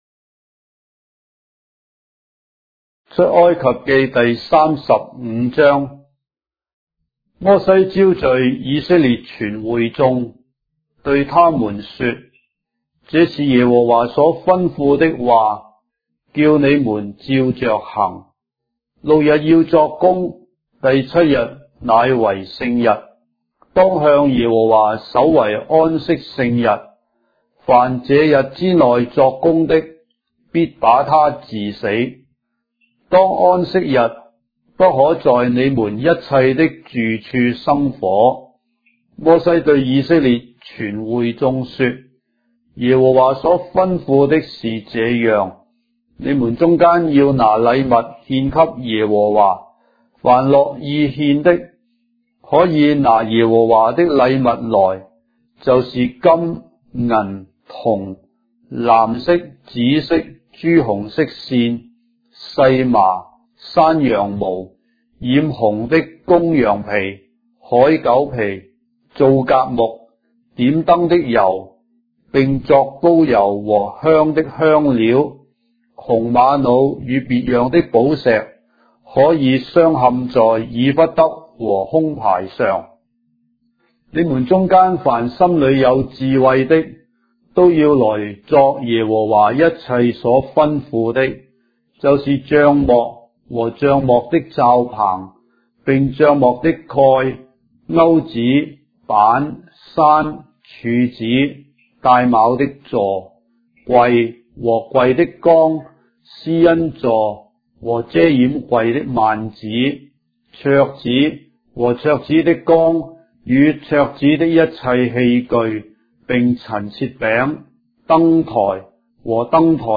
章的聖經在中國的語言，音頻旁白- Exodus, chapter 35 of the Holy Bible in Traditional Chinese